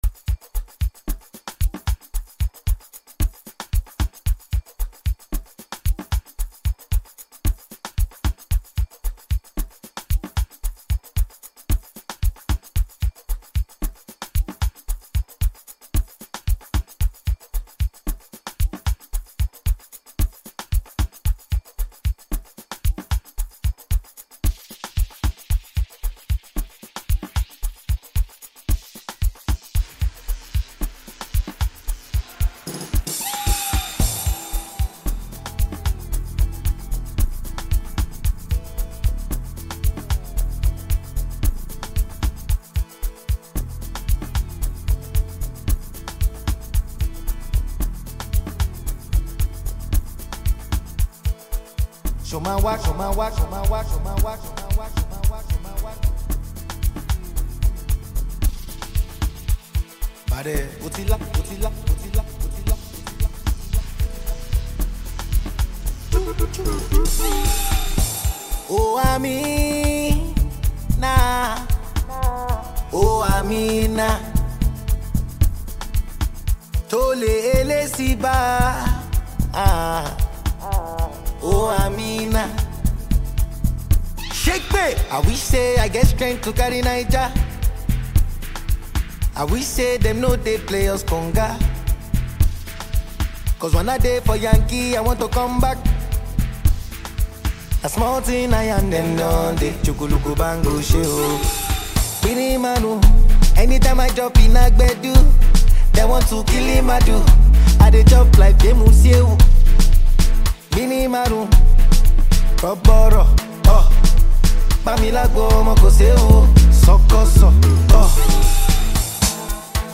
enchanting tune